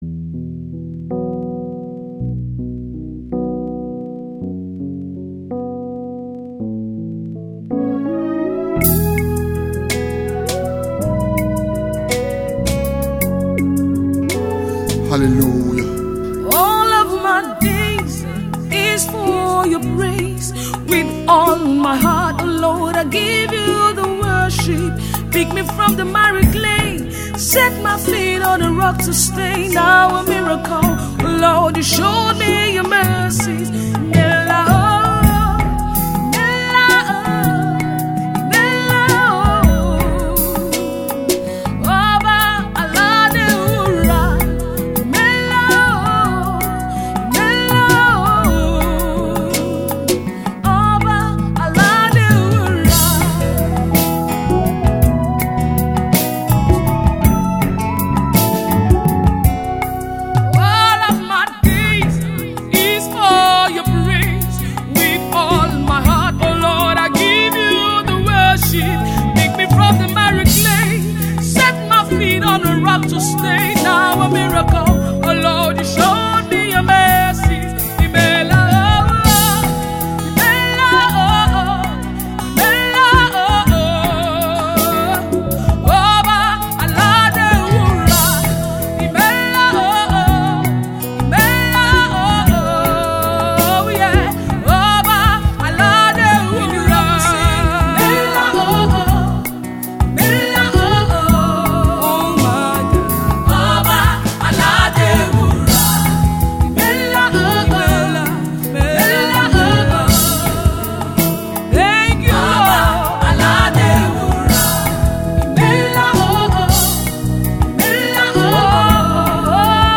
worshipper's anthem